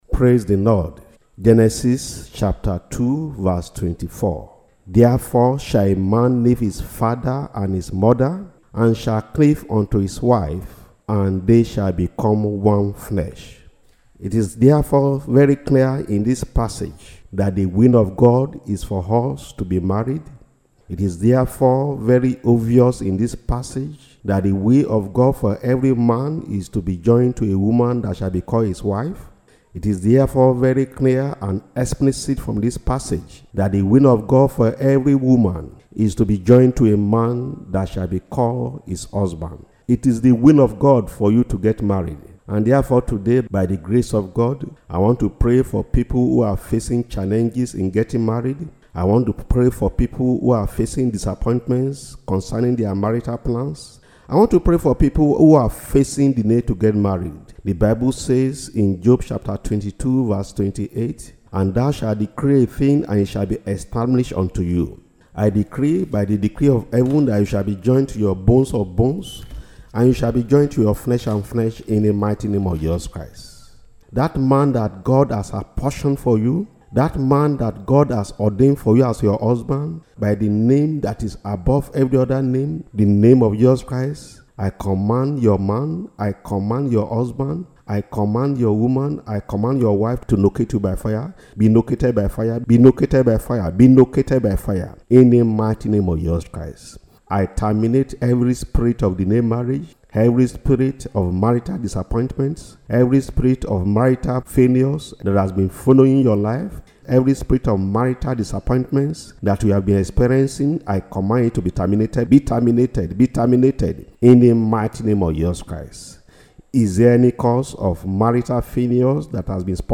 Audio sermons on marital problems: My marriage must work! - Save the World Ministry